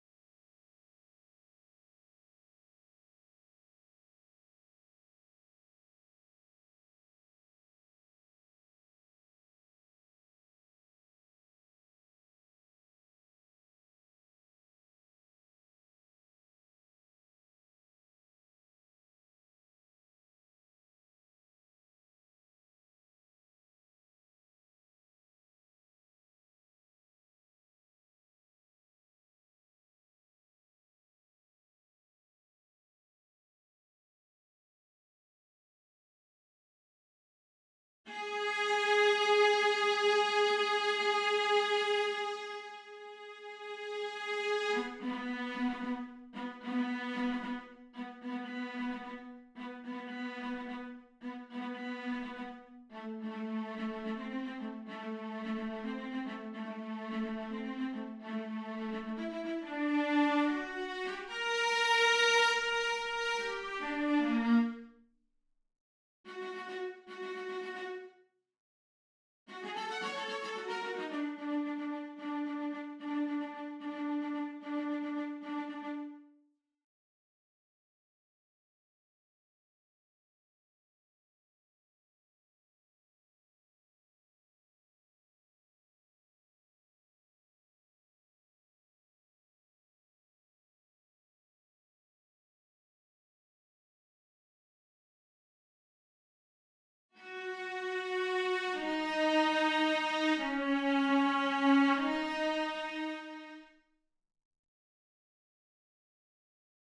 23. Bass Drum (Bass drum/Hard mallet)